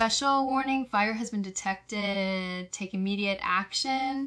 I was attempting to add voice to my Home Assistant for when the smoke detector goes off, for 🤷‍♀ reasons.